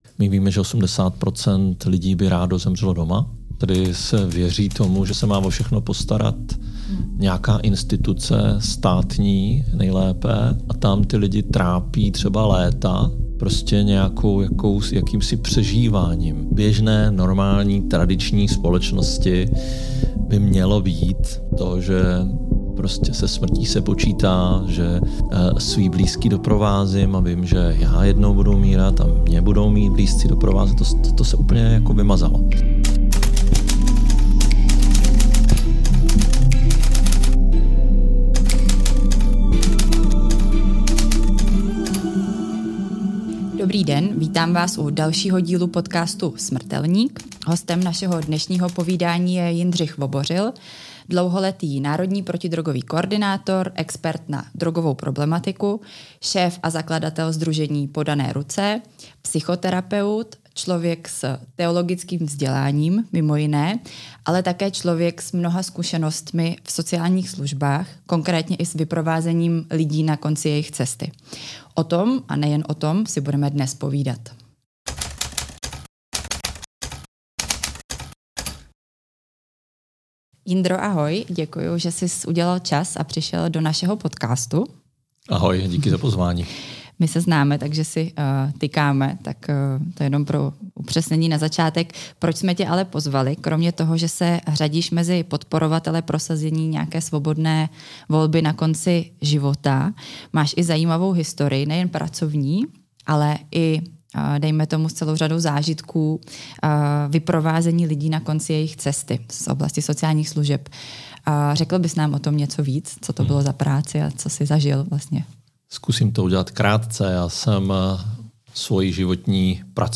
Poslechněte si tento inspirativní rozhovor.